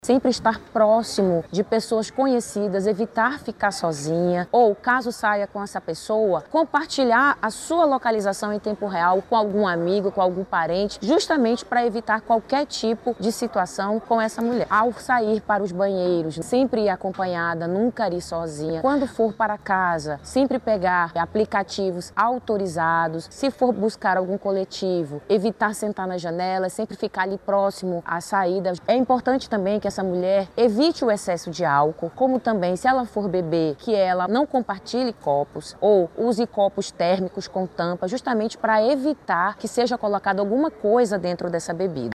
SONORA-1-ORIENTACOES-MULHERES-CARNAVAL-.mp3